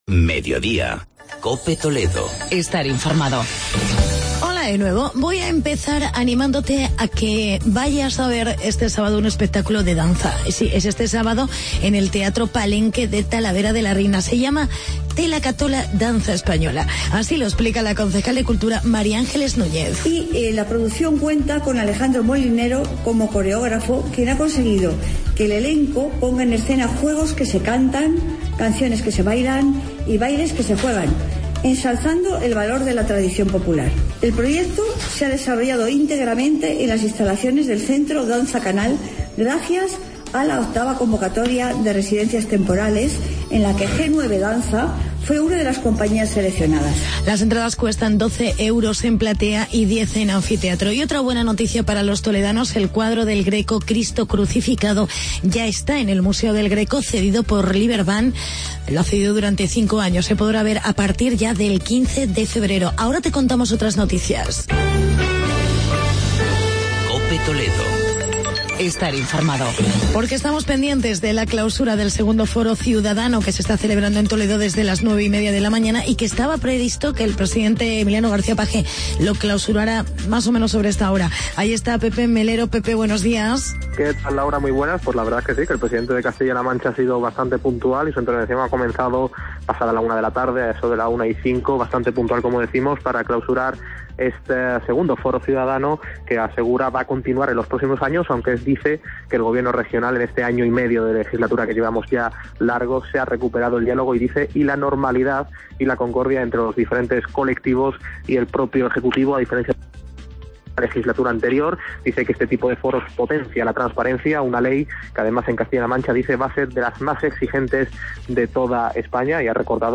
Actualidad y entrevista con la portavoz del gobierno en el Ayto de Talavera